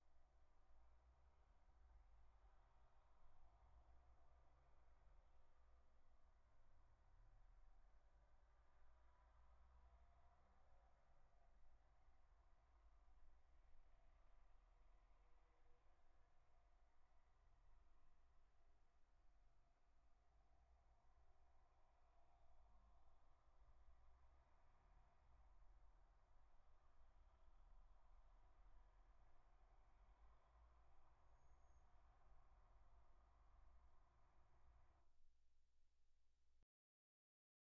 This test uses audio samples taken from three everyday scenarios: a busy street, an office, and an airplane cabin.
Street noise recordings for yourself.
street-noise.wav